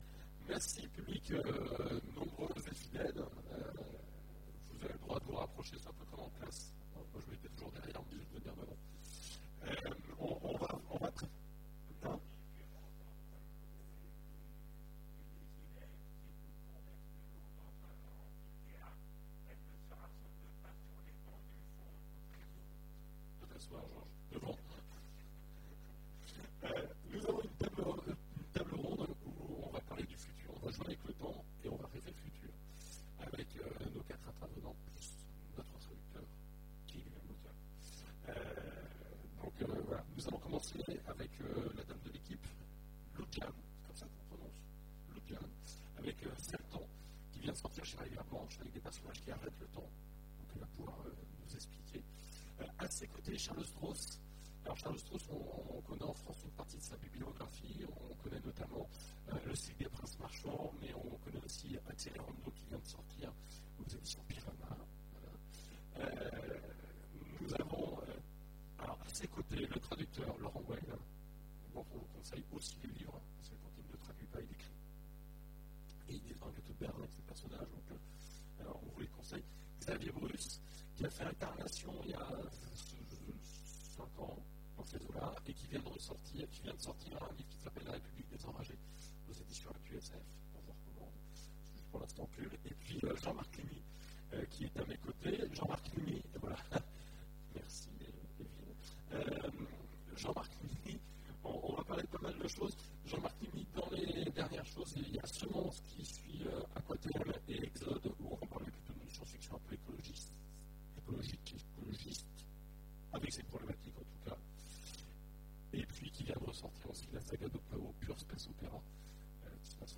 Imaginales 2015 : Conférence Et un petit tour dans le futur